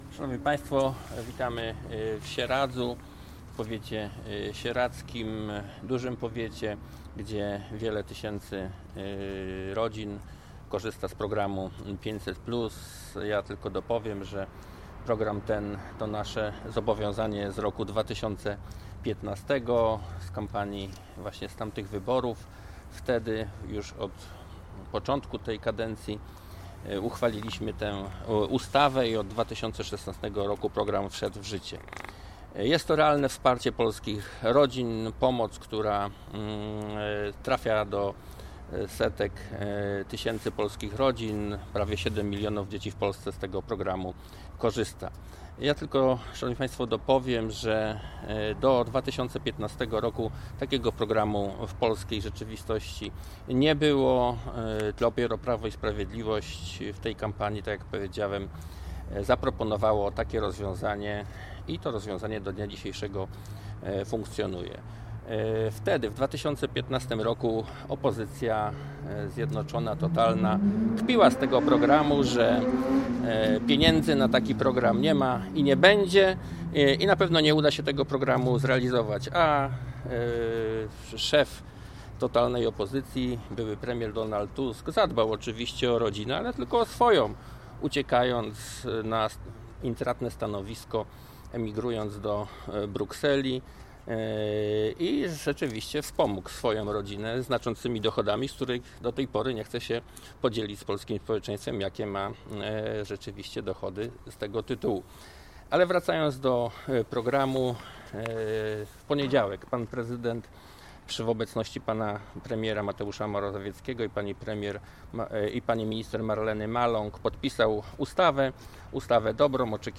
Dziś (10 sierpnia) o tym rozmawiali z mieszkańcami Sieradza politycy PiS.